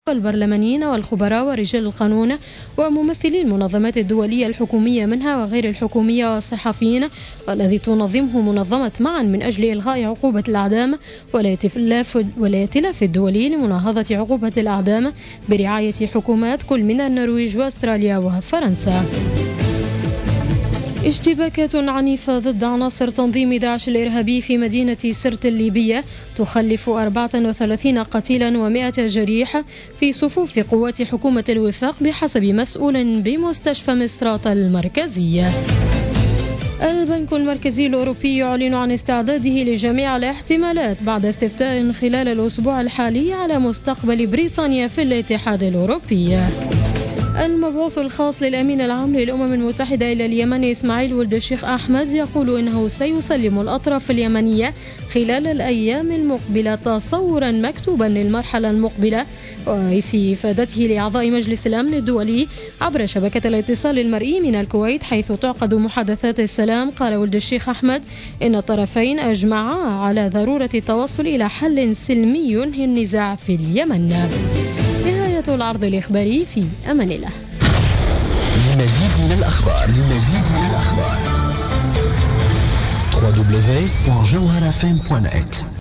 نشرة أخبار منتصف الليل ليوم الأربعاء 22 جوان 2016